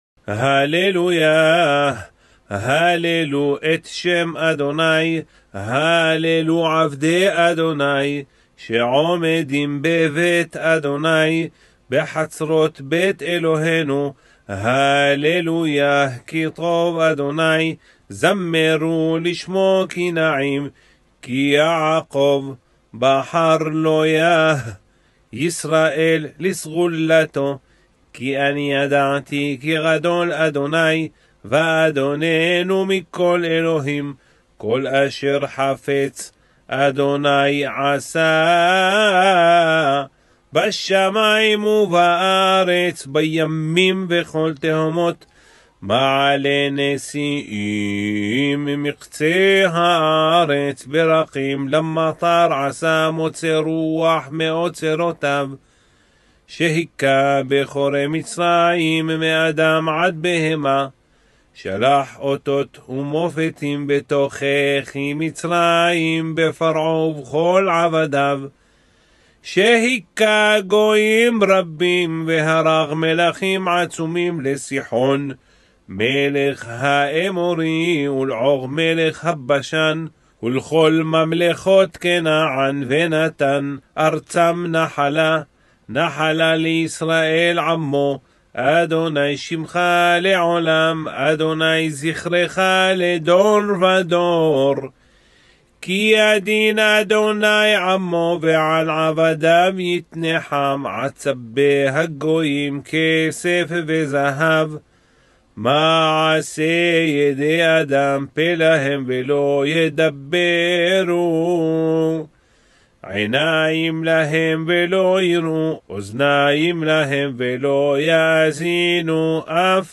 Psalm 135 Reading in Hebrew with English Translation  Prayer to Serve God.mp3